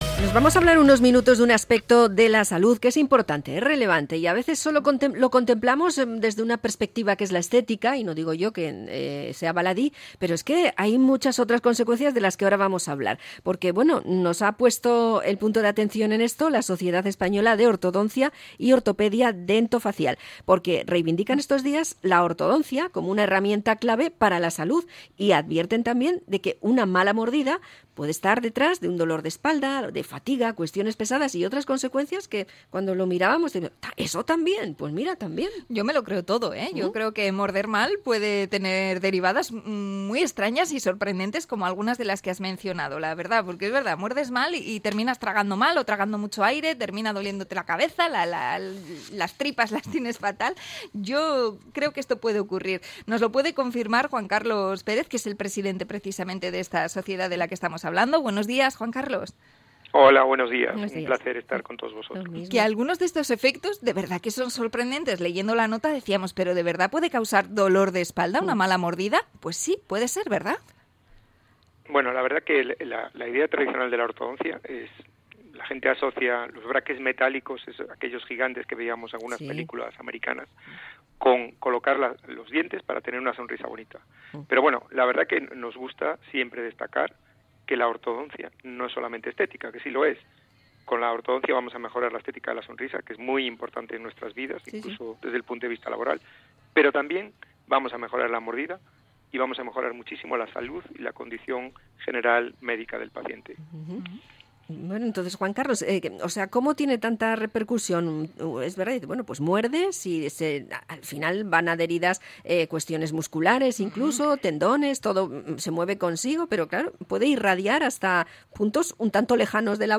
INT.-ORTODONCIA.mp3